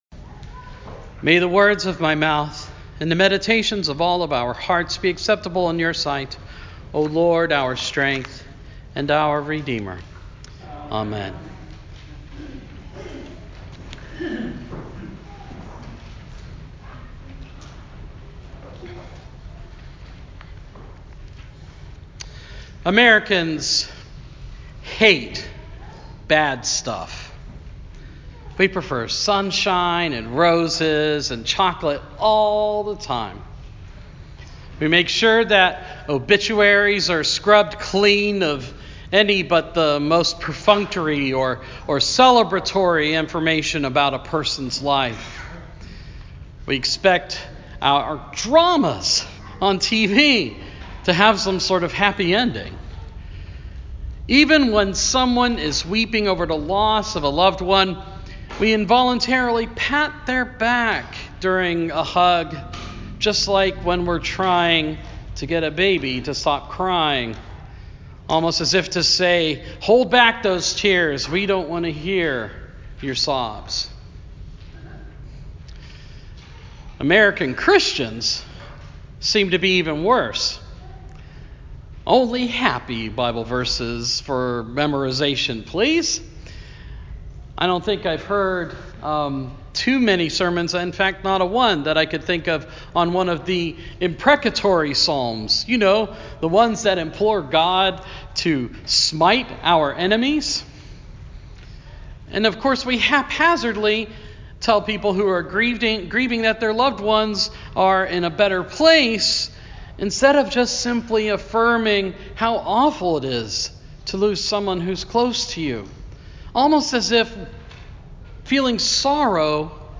Sermon – Proper 20 – 2019